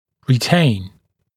[rɪ’teɪn][ри’тэйн]держать, удерживать, сохранять